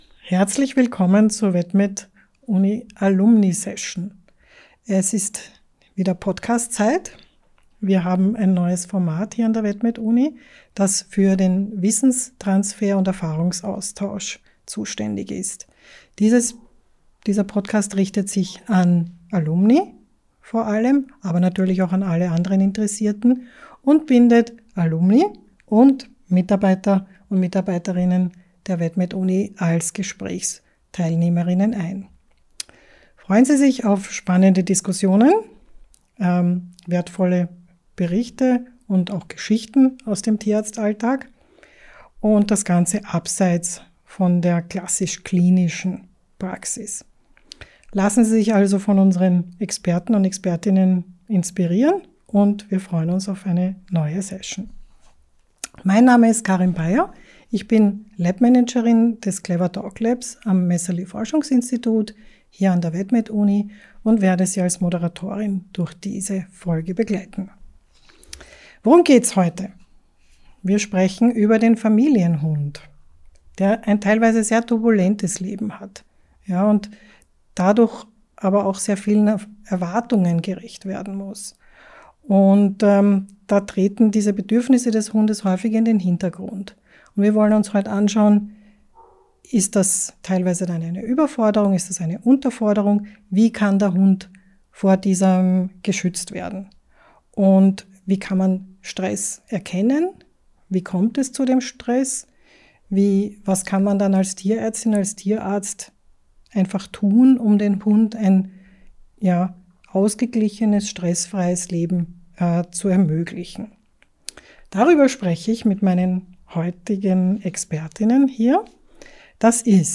Das und vieles mehr diskutieren die Expert:innen in dieser Episode.